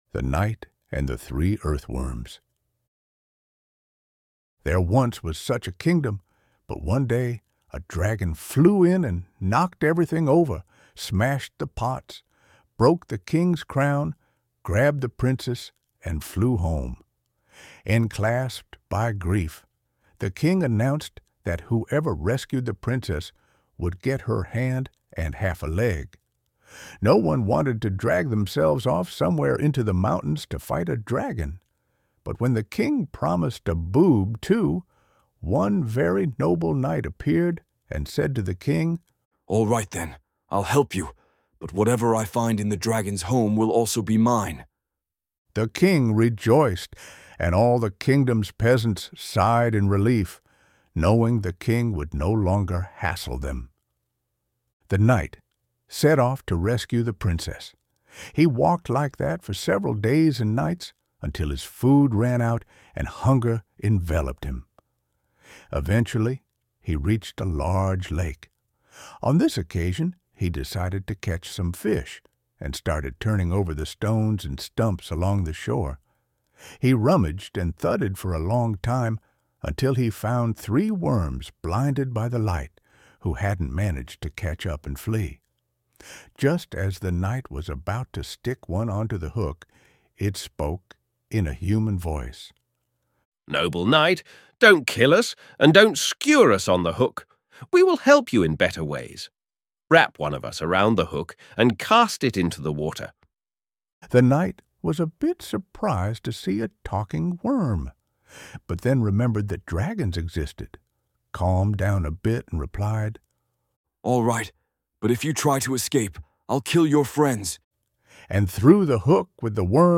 Human tales, translated from Lithuanian, with illustrations and music made by thinking machines.